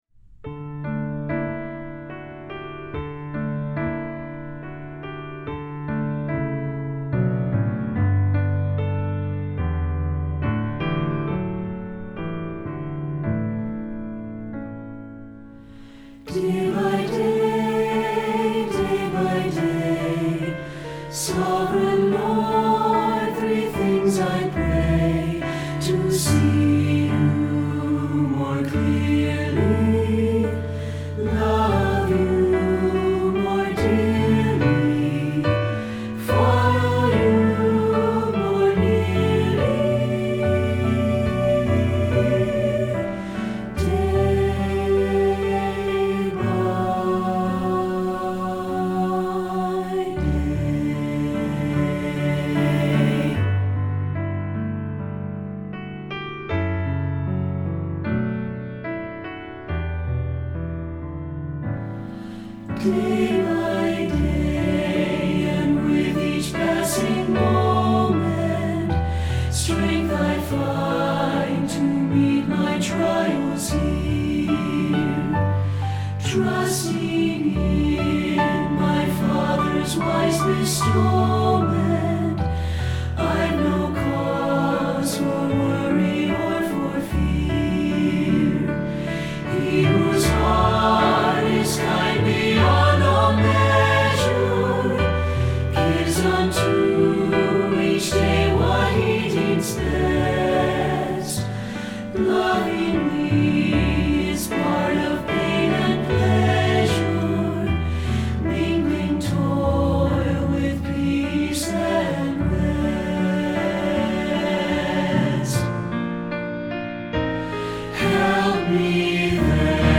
Feuillet pour Chant/vocal/choeur - SATB